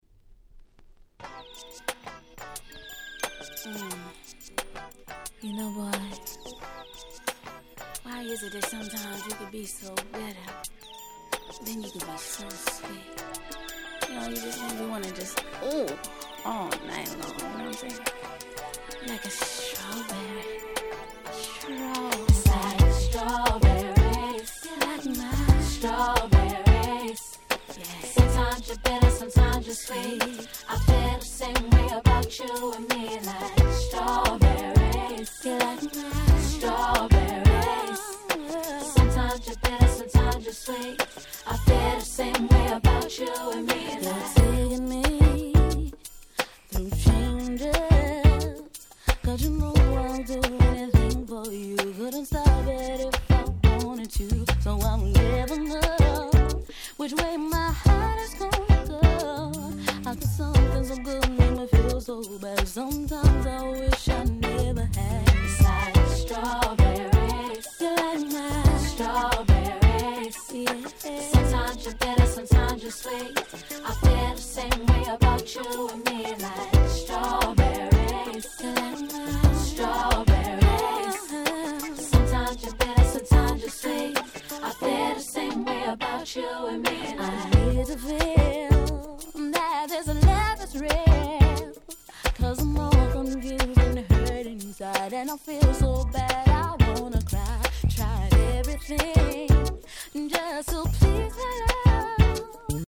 98' Nice R&B LP !!
統一したNeo Soul感が素晴らしい名盤中の名盤です！